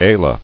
[a·la]